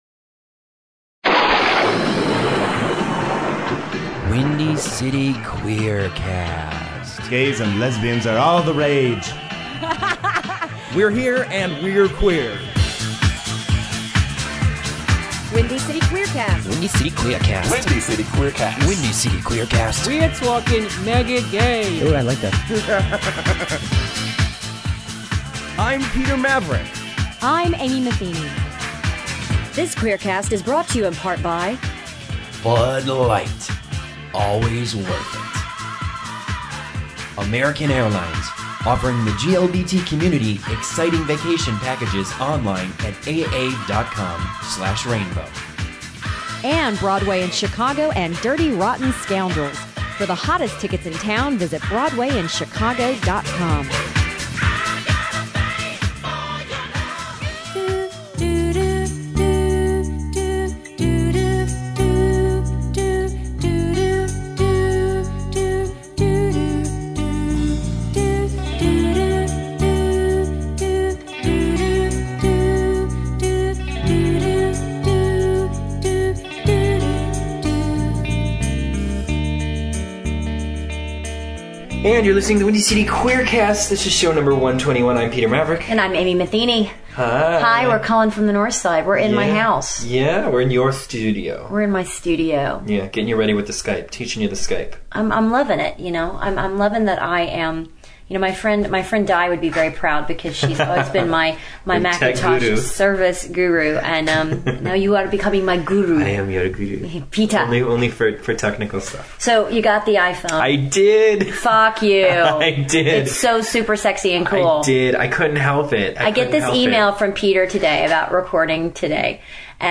At the Center on Halsted